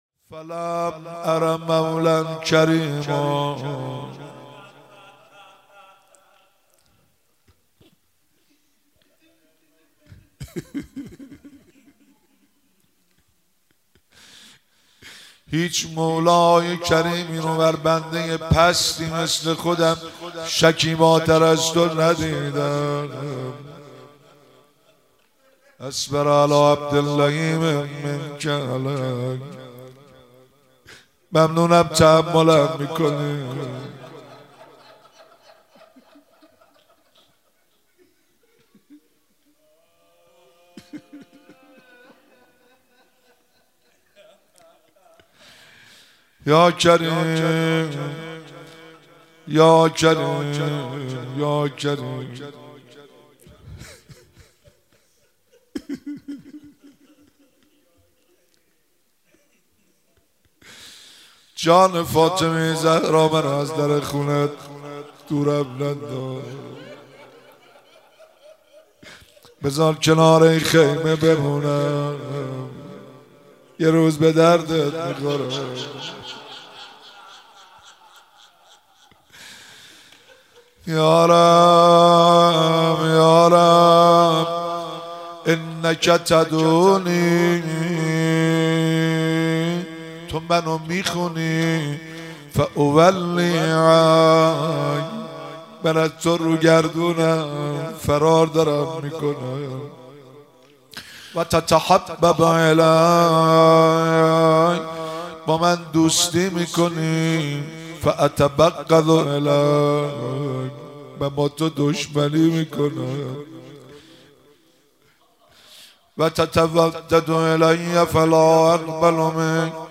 مراسم مناجات خوانی شب اول ماه رمضان 1444